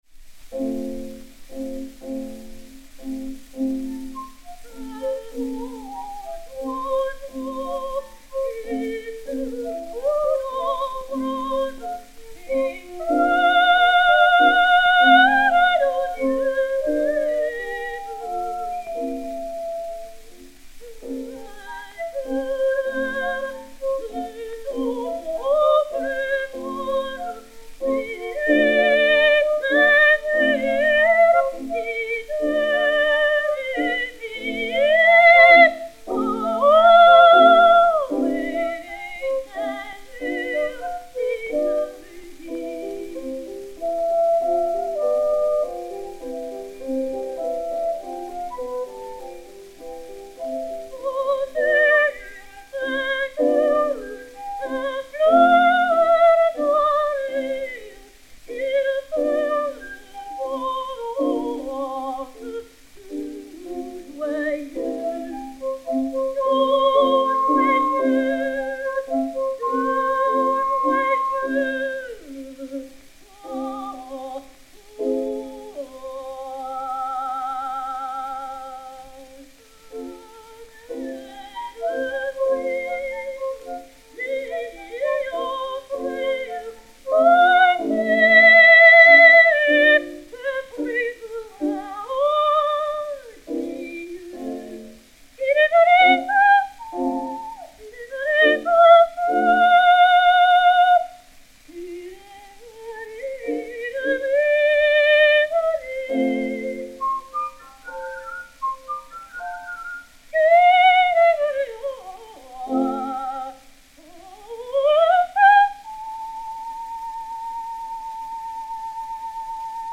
Piano
flûte
C4425-1, enr. aux USA le 22 avril 1907